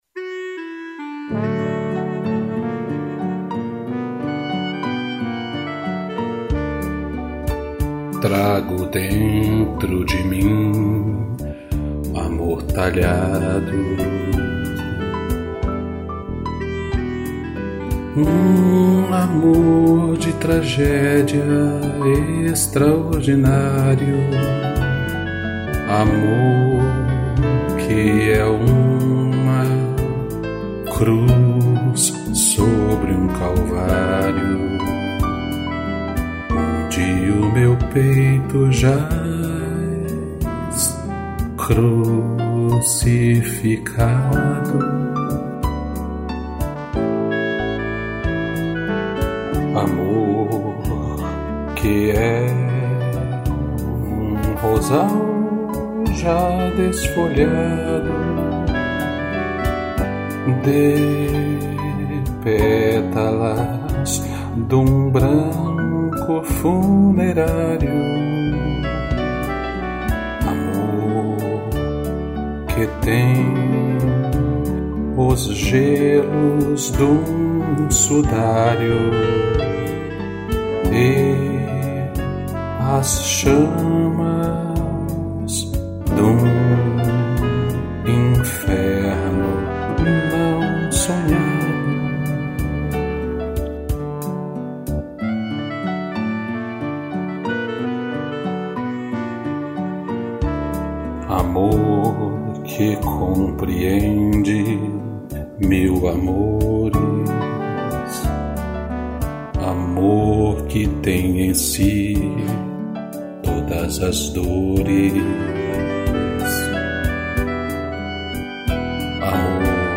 piano, clarineta e trombone